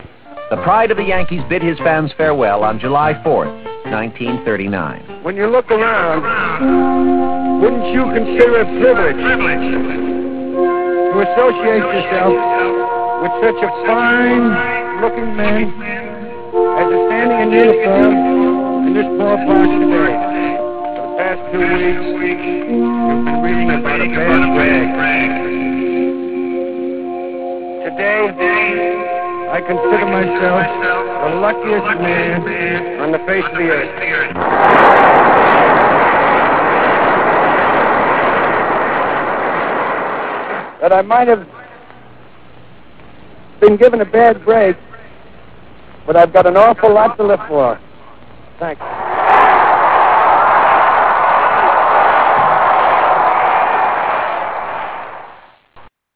The Luckiest Man Speech in RealAudio